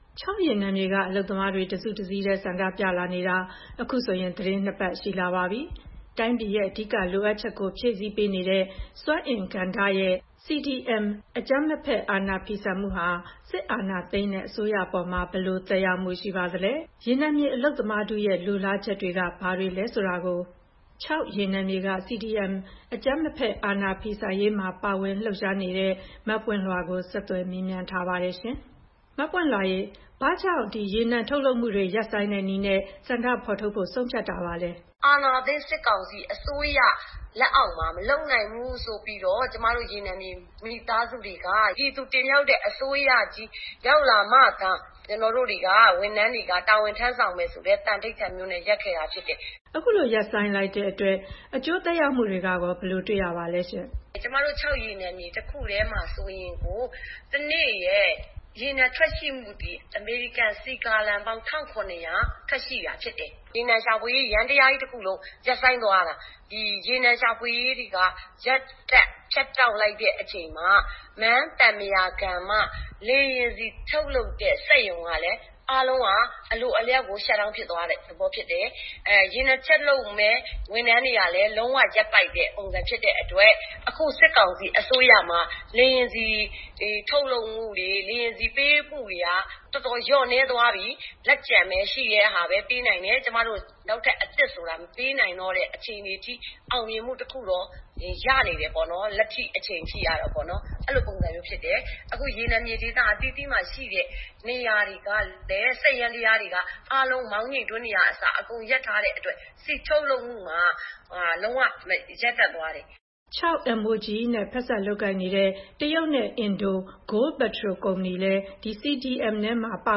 ချောက်ရေနံမြေဆန္ဒပြသူ တဦးနဲ့ ဆက်သွယ်မေးမြန်းချက်